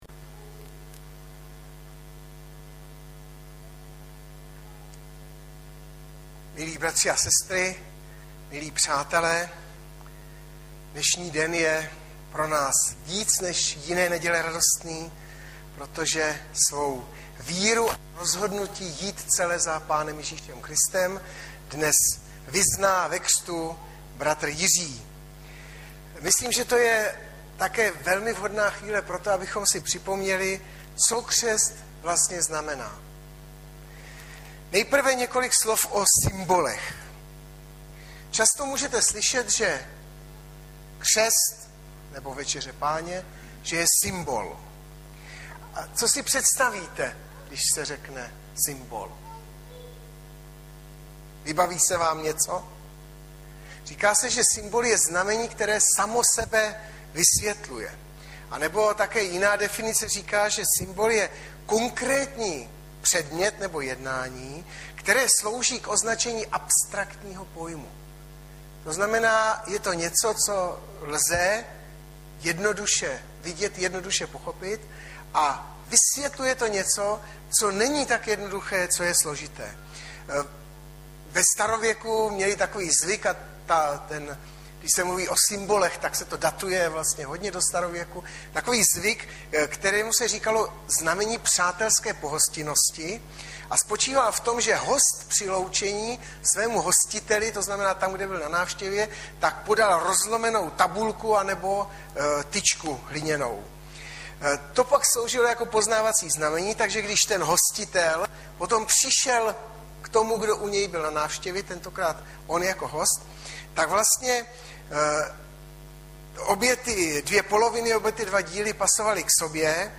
- Ř 6,1-4 Audiozáznam kázání si můžete také uložit do PC na tomto odkazu.